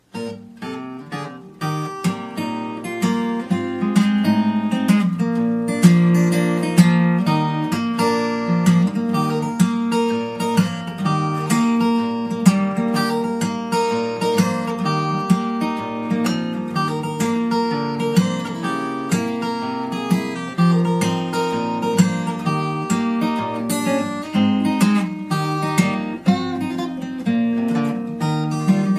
a catchy melody
With its uplifting tune and memorable lyrics